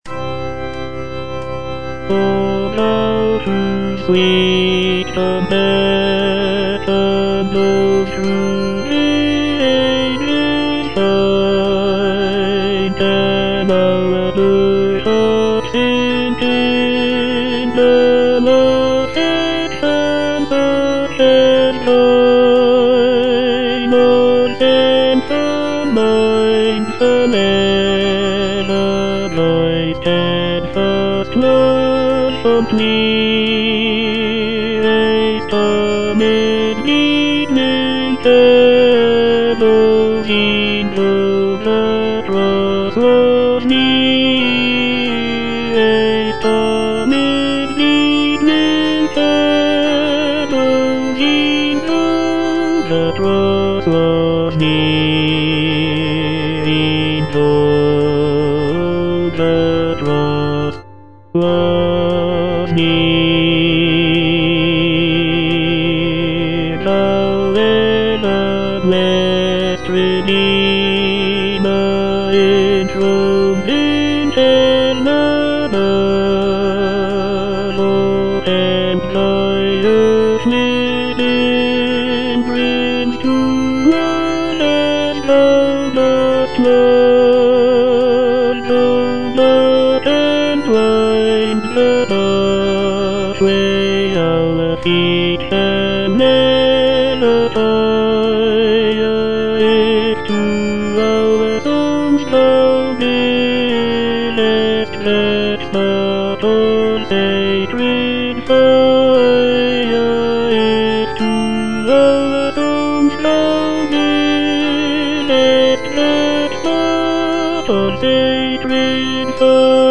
O Thou Whose sweet compassion - Tenor (Voice with metronome) Ads stop: